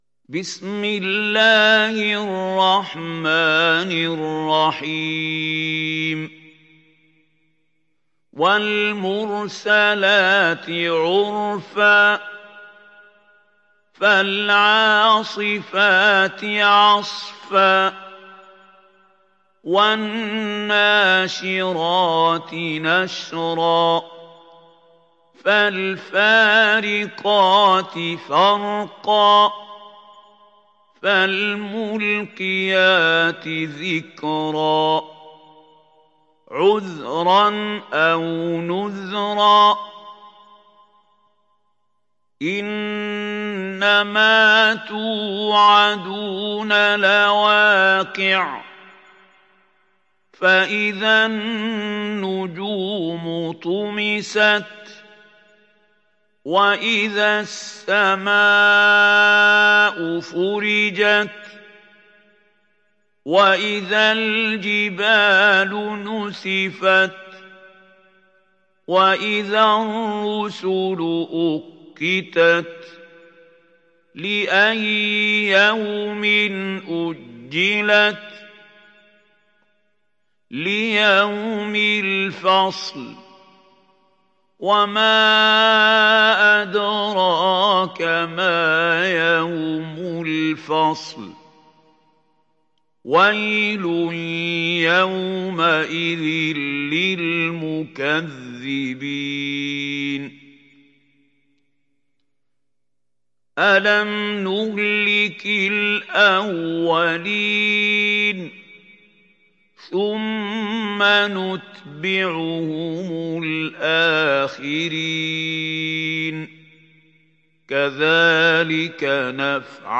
Sourate Al Mursalat Télécharger mp3 Mahmoud Khalil Al Hussary Riwayat Hafs an Assim, Téléchargez le Coran et écoutez les liens directs complets mp3